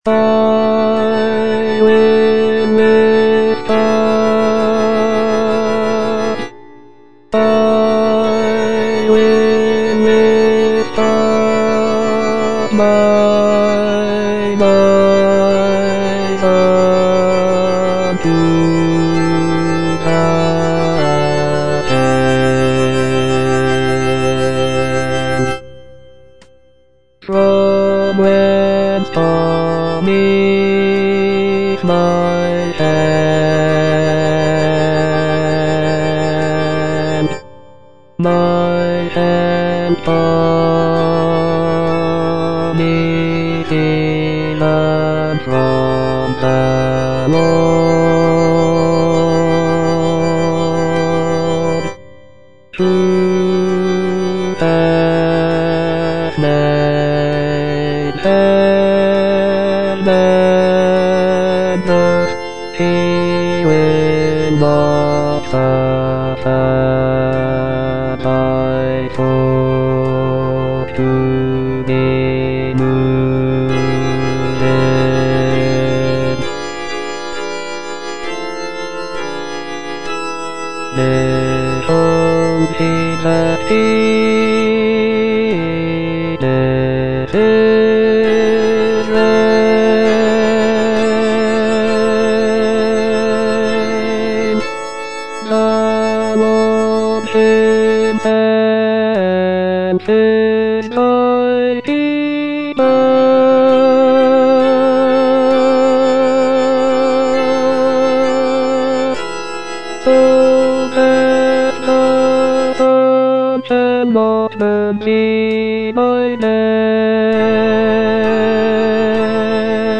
Bass II (Voice with metronome)